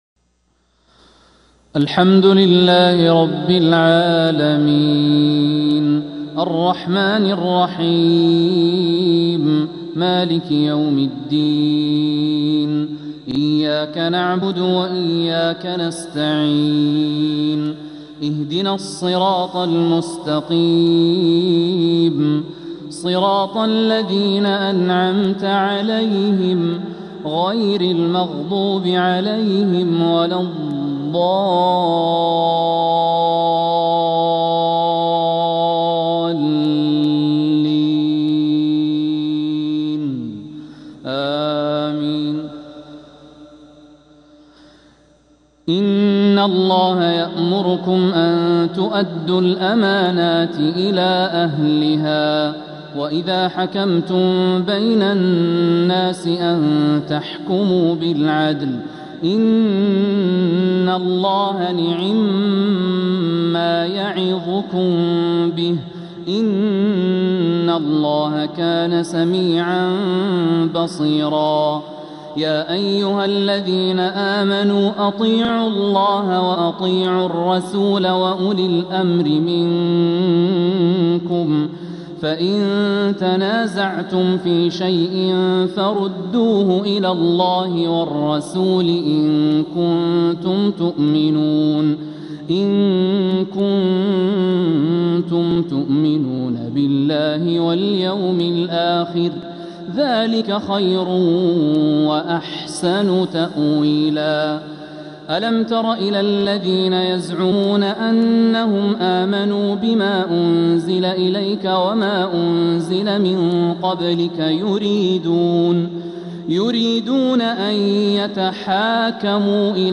فجر الأحد 11 محرم 1447هـ من سورة النساء 58-68 | Fajr prayer from Surat An-Nisa 6-7-2025 > 1447 🕋 > الفروض - تلاوات الحرمين